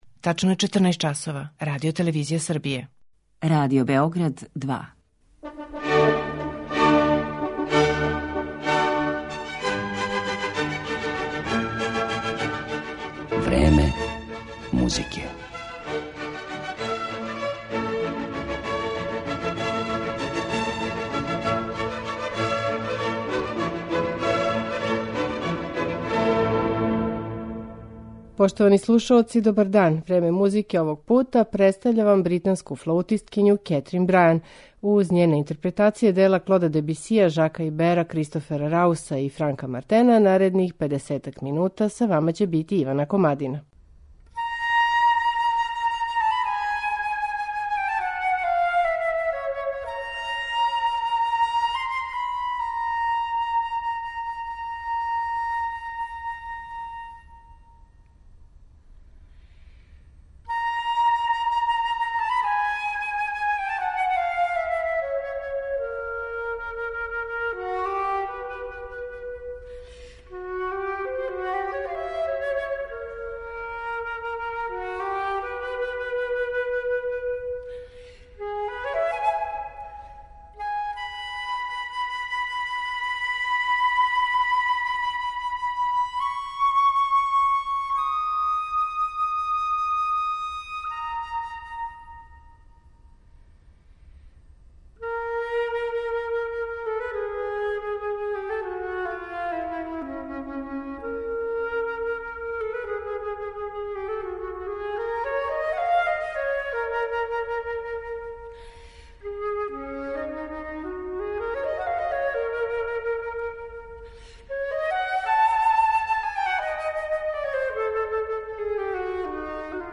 младу британску флаутисткињу